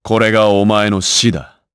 Lusikiel-Vox_Skill2_jp.wav